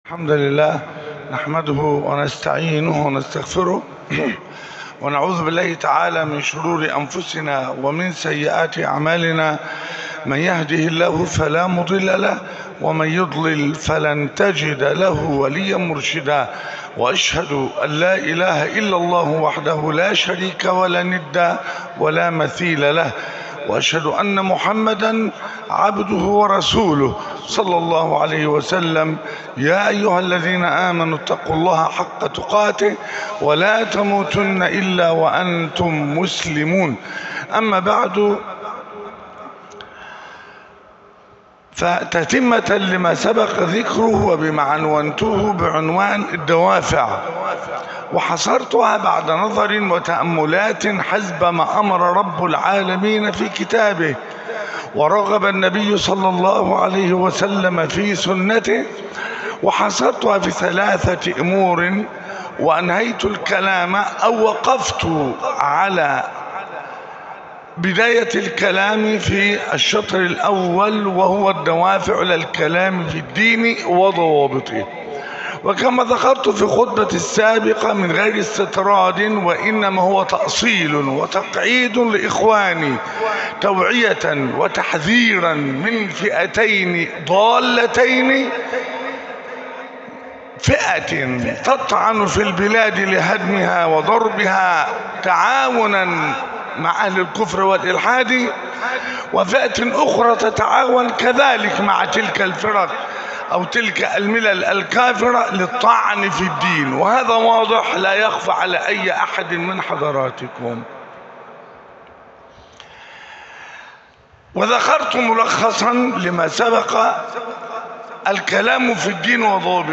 خطبة: الدوافع (الحلقة الثانية)